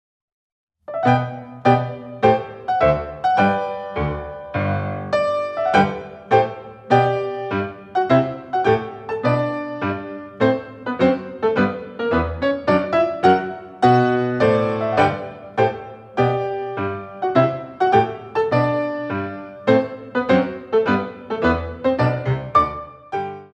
Grands Battements